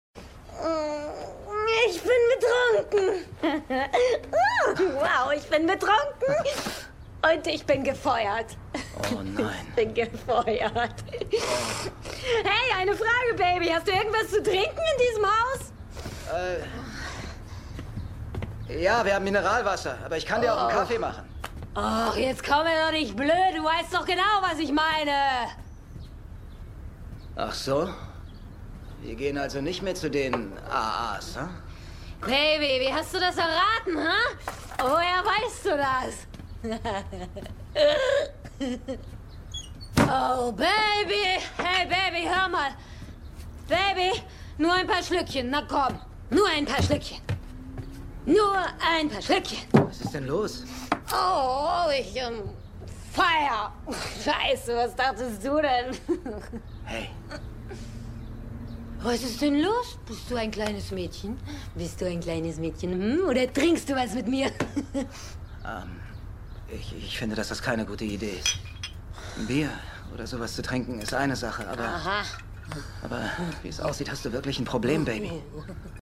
Synchronausschnitt